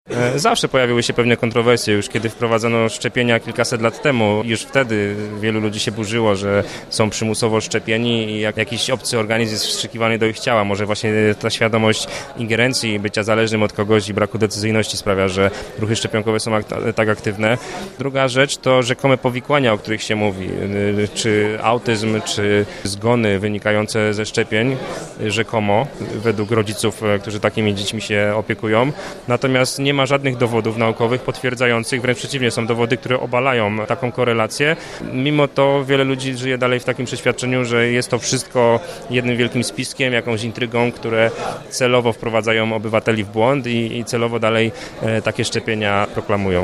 Podczas wykładu poruszony został również temat szczepionek.
prelekcja